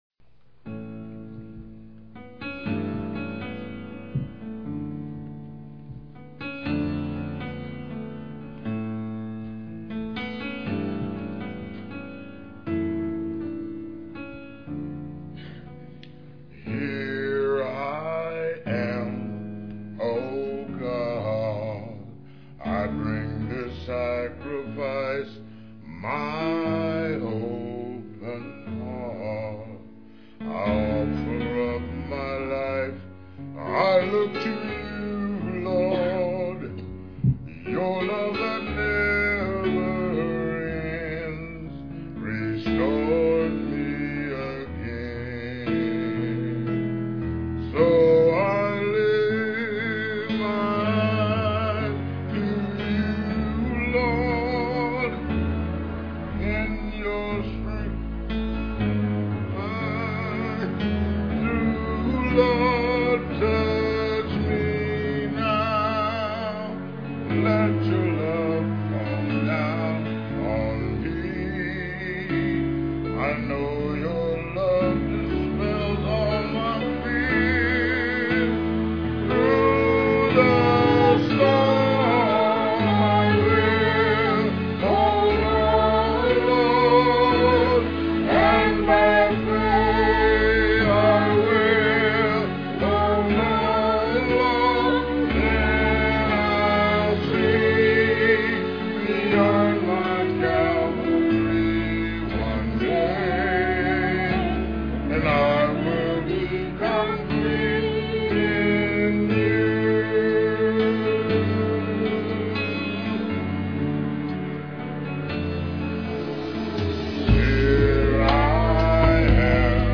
2006 Scripture: John 3:16-17. Scripture Reading, Message